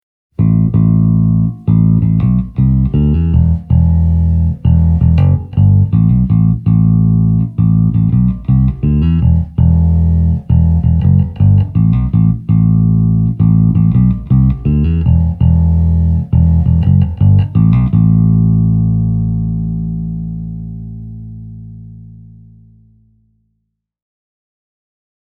Testasin Warwick LWA 1000 -vahvistinta saman valmistajan laadukkaan WCA 410 -kaapin kautta.
Toisin kuin joidenkin muiden valmistajien bassovahvistimet, LWA välittää sähköbasson omaa soundia käytännössä täysin vääristämättä eteenpäin.
Hyvän kuvan Warwickin voimasta saa, kun kuuntelee näitä ääninäytteitä läpi: taustalla treenikämpän kattovalaisimien ritilät sirisevät nimittäin mukana, vaikka LWA:n master-volyymi ei ole avattu edes puoleksi:
Soundi on puhdas ja dynaaminen, ja äänenpainetta riittää vaikkapa muillekin jaettavaksi.